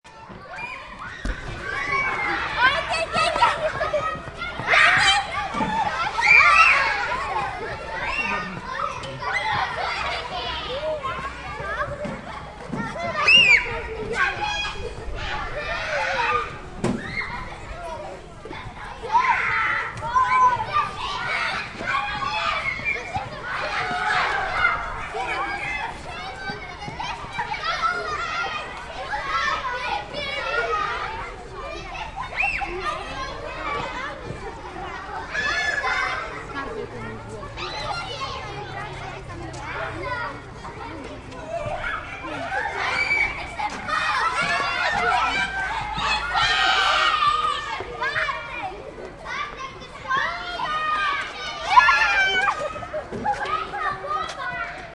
Download Children sound effect for free.
Children